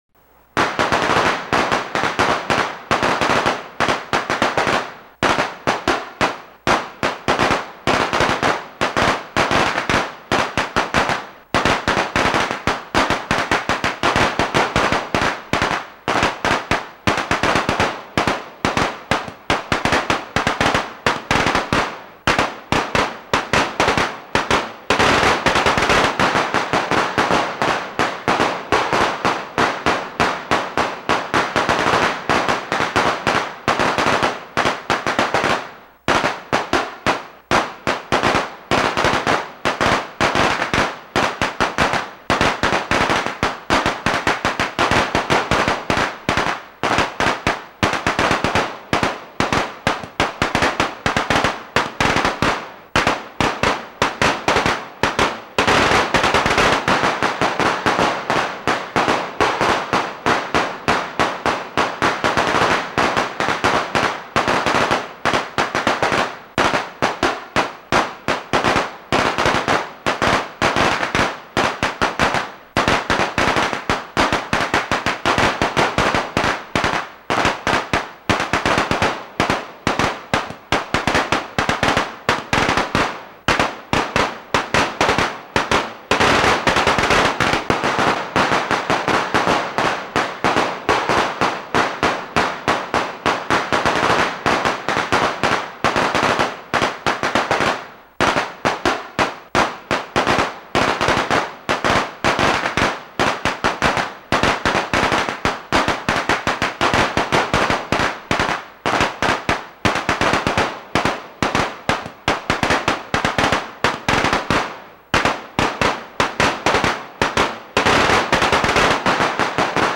這邊提供三種稍微不一樣的鞭炮聲音效供大家下載：
鞭炮聲-1